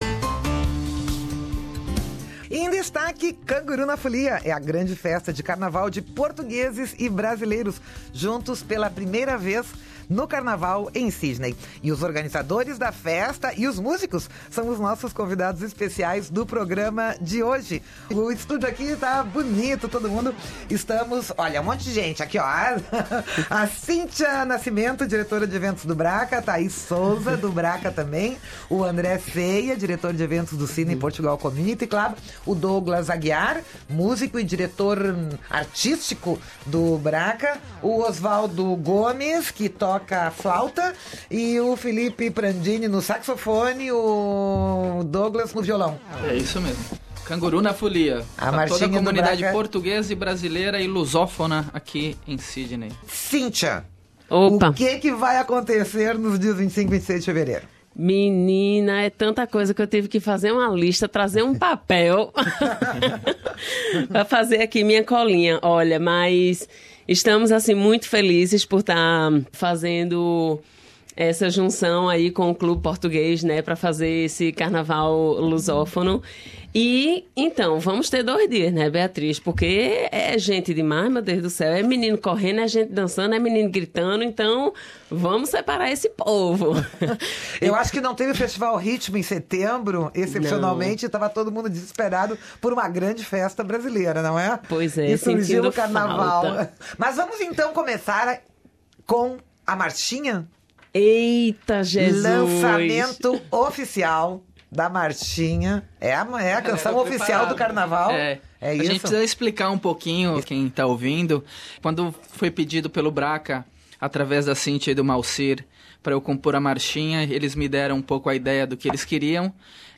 BraCCA e Clube Português de Sydney na Rádio SBS Source: by BW SBS Portuguese